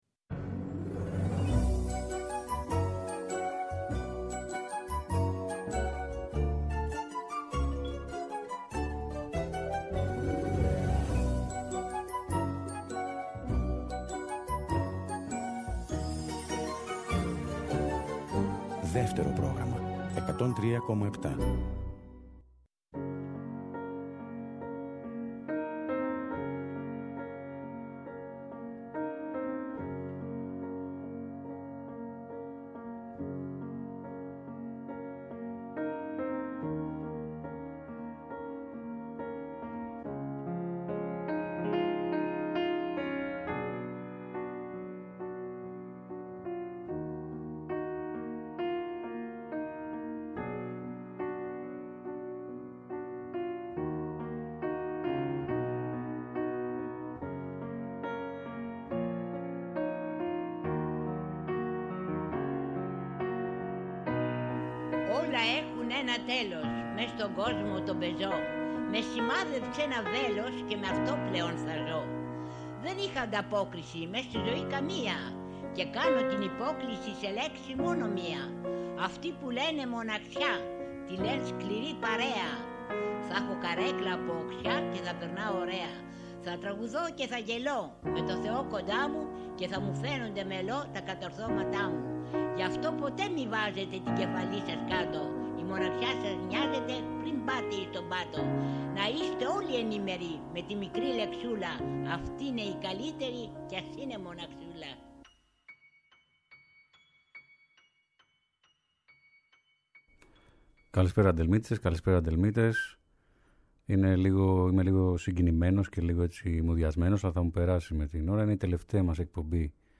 Δευτέρα 28 Ιουνίου στις 6 το απόγευμα και στην τελευταία “Αντέλμα” ο Στάθης Δρογώσης διαλέγει τραγούδια που ζήτησαν οι ακροατές. 36 εκπομπές πέρασαν.